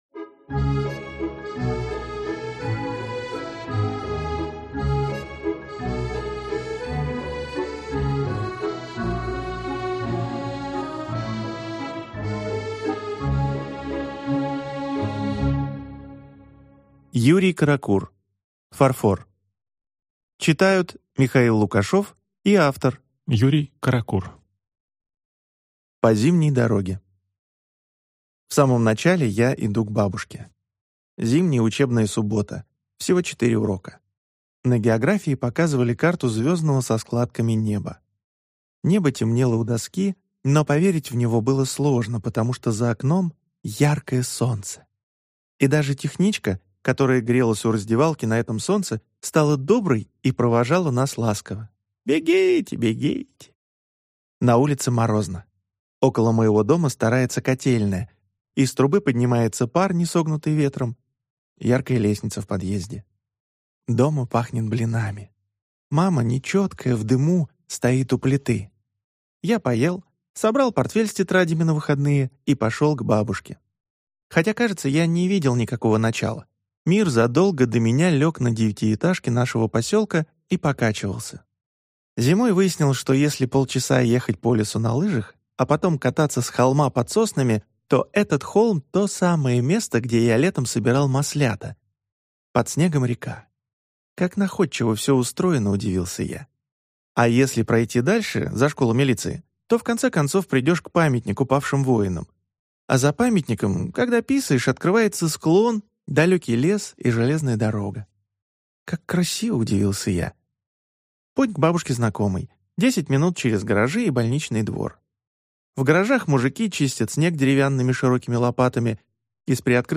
Aудиокнига Фарфор